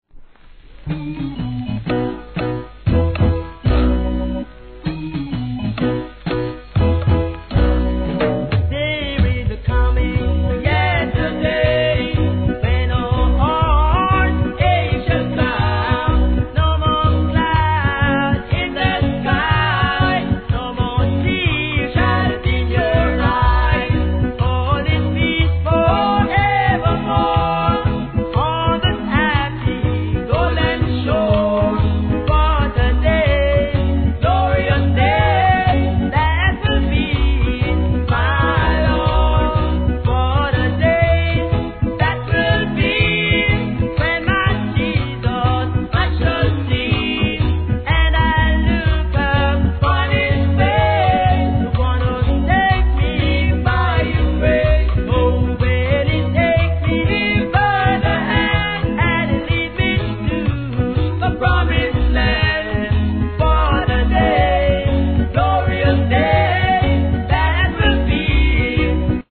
REGGAE
NICEオルガン＆ベースなRHYTHMで聴かせます。